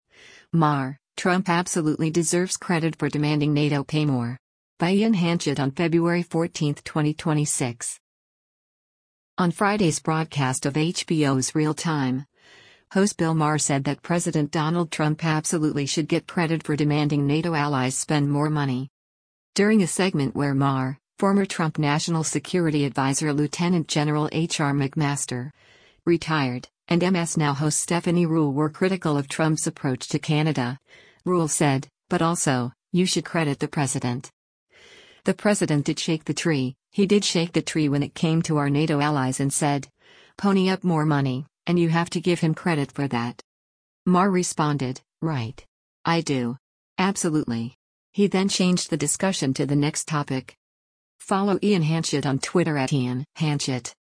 On Friday’s broadcast of HBO’s “Real Time,” host Bill Maher said that President Donald Trump “Absolutely” should get credit for demanding NATO allies spend more money.
During a segment where Maher, former Trump National Security Adviser Lt. Gen. H.R. McMaster (Ret.), and MS NOW host Stephanie Ruhle were critical of Trump’s approach to Canada, Ruhle said, “But also, you should credit the President.